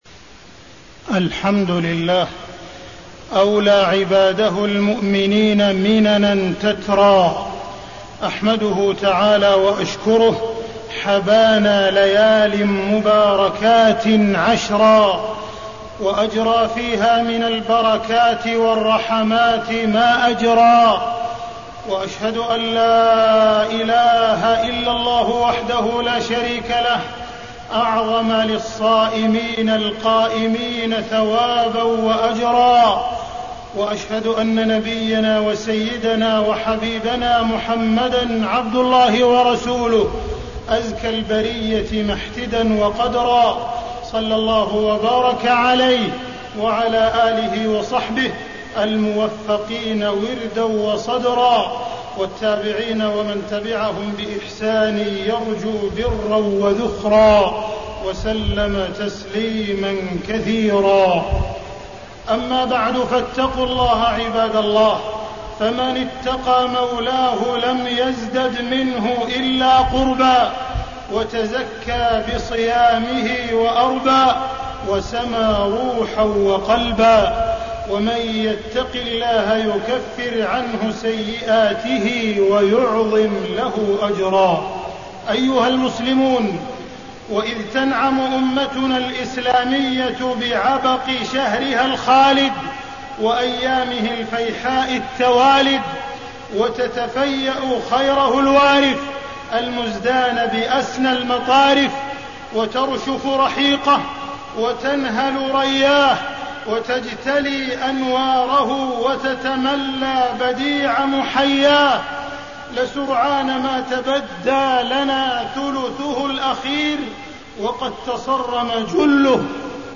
تاريخ النشر ٢٤ رمضان ١٤٣١ هـ المكان: المسجد الحرام الشيخ: معالي الشيخ أ.د. عبدالرحمن بن عبدالعزيز السديس معالي الشيخ أ.د. عبدالرحمن بن عبدالعزيز السديس فضل ليالي العشر الأوآخر من رمضان